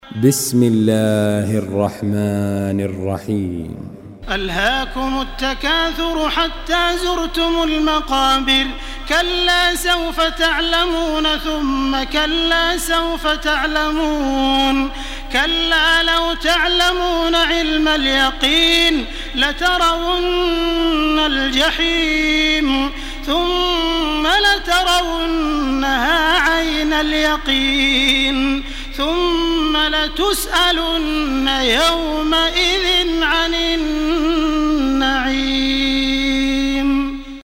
Surah আত-তাকাসুর MP3 by Makkah Taraweeh 1429 in Hafs An Asim narration.
Murattal